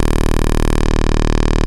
OSCAR 13 D#1.wav